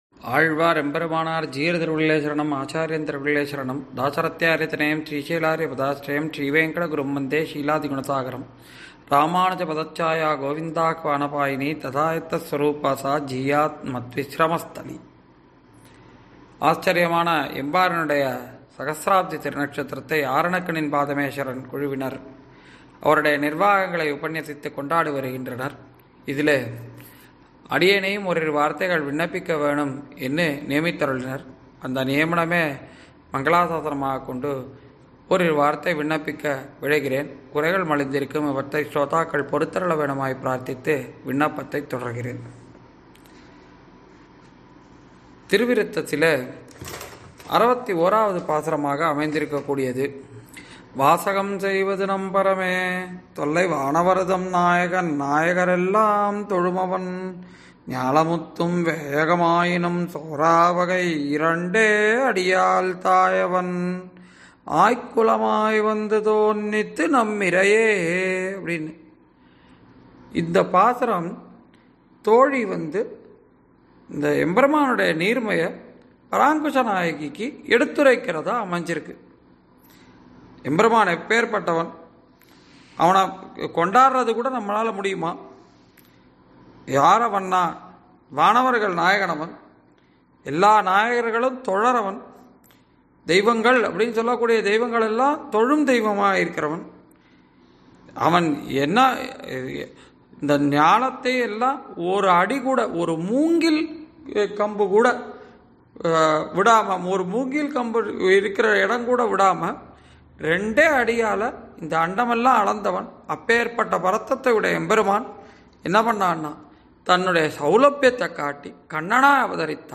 Thalaiyil Pirambugal Vizhum ..| Embar 1000th Thirunakshathira Upanyasangal | Thai Month-2026 – Archai